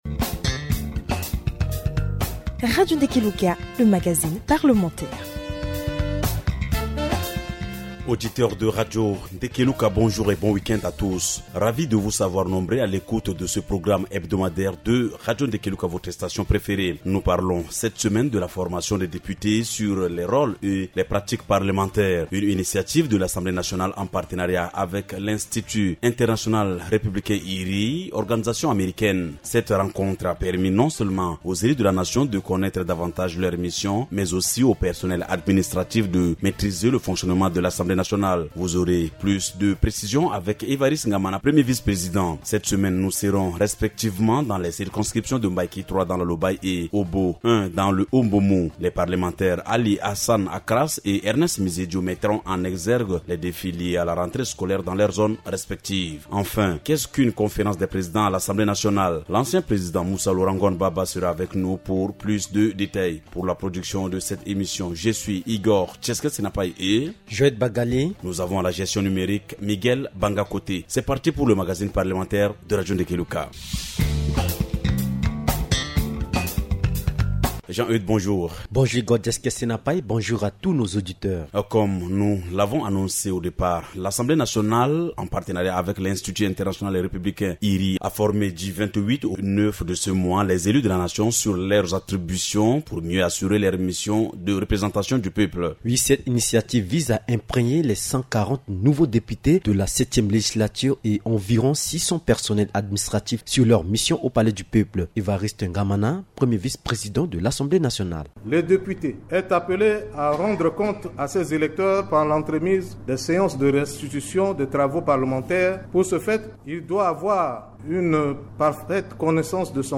reçoivent Evariste Ngamana, 1er Vice-président de l’Assemblée nationale.